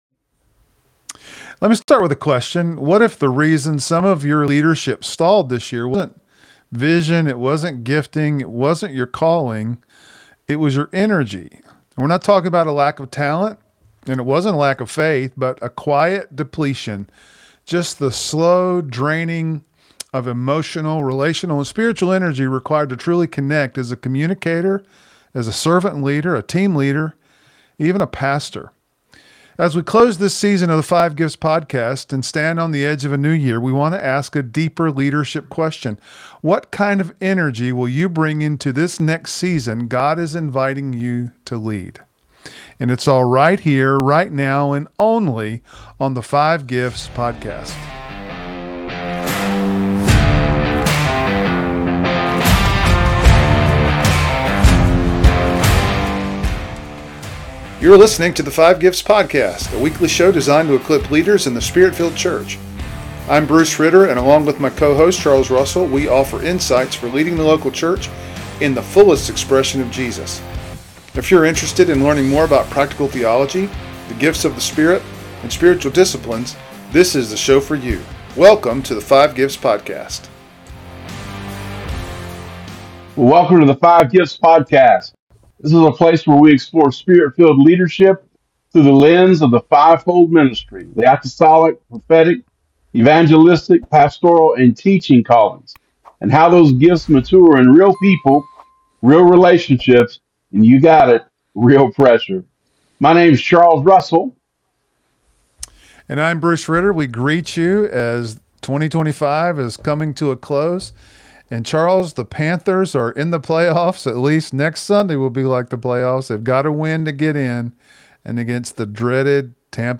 With the New Year approaching, they invite leaders to examine where their emotional, relational, and spiritual energy has been spent—and where God may be calling them to reinvest it. This episode weaves fivefold leadership insight, emotional health, and biblical wisdom into a reflective conversation designed to help leaders finish one season well and enter the next with intentionality, presence, and renewed influence.